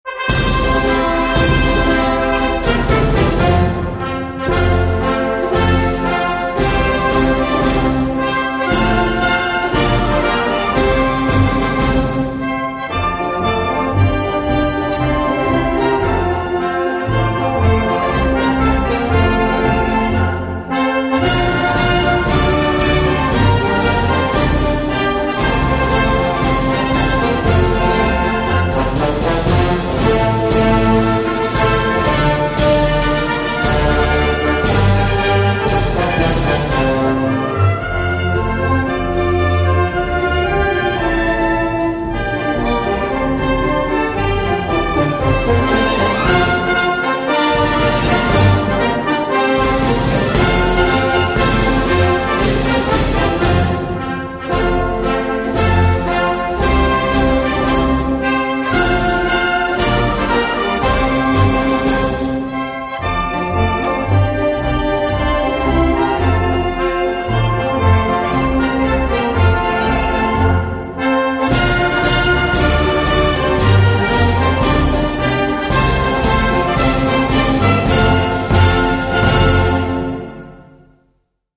銅管樂版、管弦樂版、合唱版、加长版
經典製作，完美音質體現